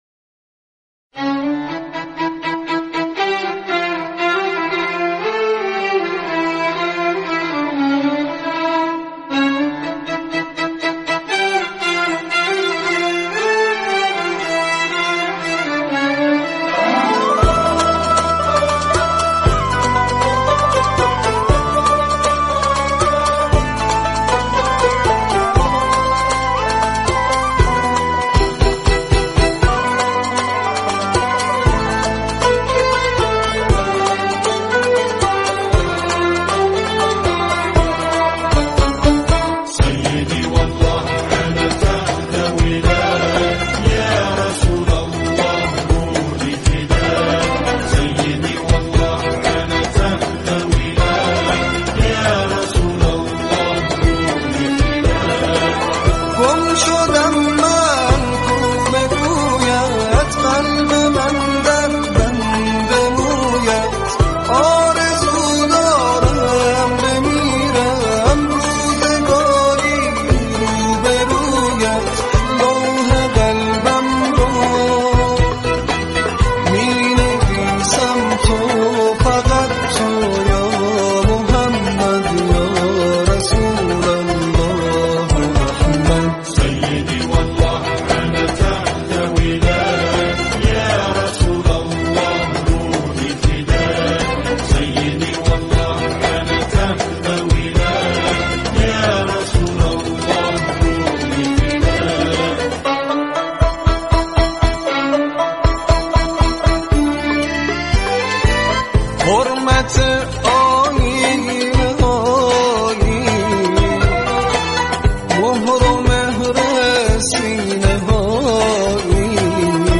سرودهای ۱۷ ربیع الاول
با همکاری گروه کُر